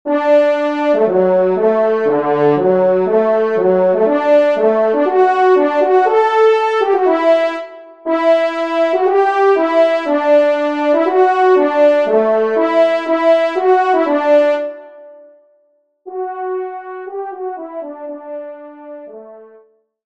Genre :  Musique Religieuse pour Trois Trompes ou Cors
Pupitre 1°Trompe